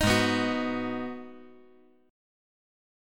Abm7 Chord
Listen to Abm7 strummed